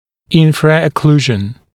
[ˌɪnfrəə’kluːʒn][ˌинфрээ’клу:жн]инфраокклюзия